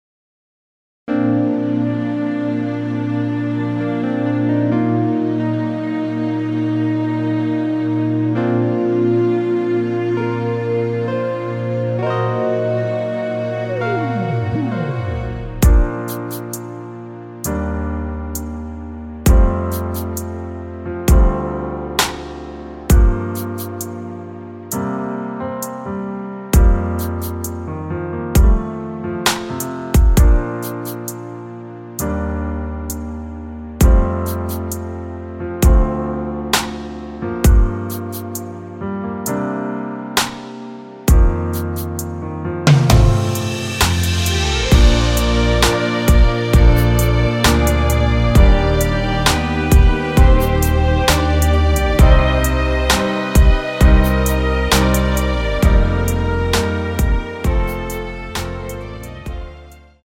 원키에서(-1)내린 MR입니다.
Bb
앞부분30초, 뒷부분30초씩 편집해서 올려 드리고 있습니다.